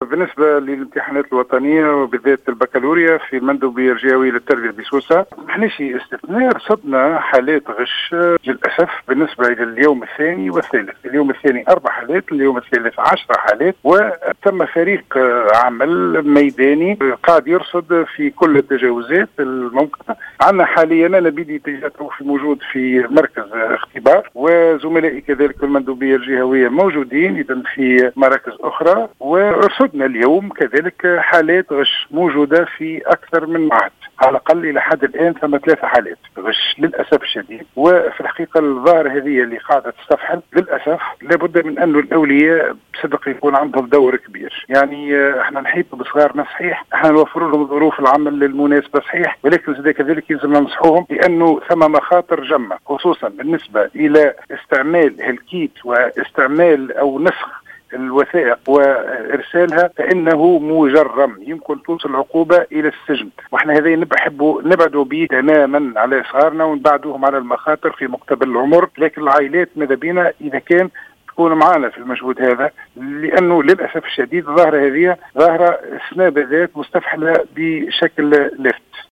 وأوضح الزبيدي في تصريح للجوهرة أف أم أنه في اليوم الثاني من الإمتحانات تم رصد 4 حالات و10 حالات في اليوم الثالث، مضيفًا أن فريق عمل ميداني يقوم برصد كل الإخلالات وقد تم اليوم رصد 3 حالات غش في أكثر من مركز إختبار.